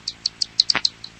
PixelPerfectionCE/assets/minecraft/sounds/mob/bat/idle3.ogg at ca8d4aeecf25d6a4cc299228cb4a1ef6ff41196e